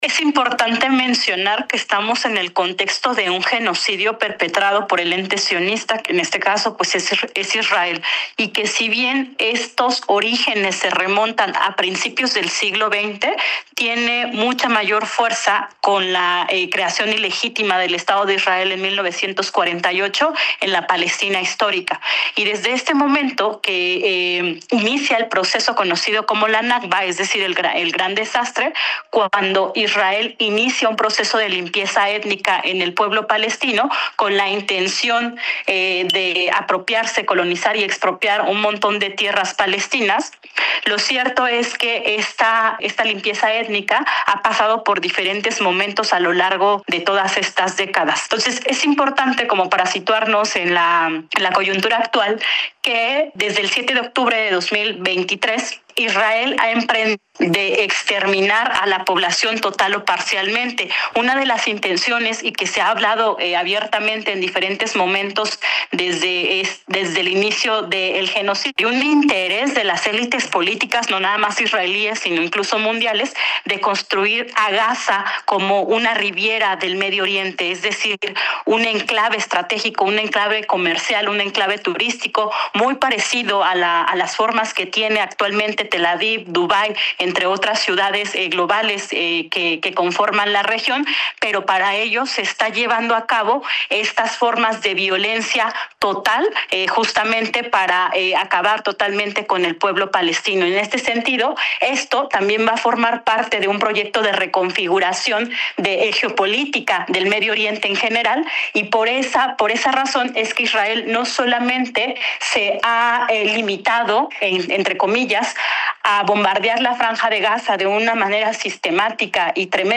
16-ENTREVISTA-ISRAEL-IRAN.mp3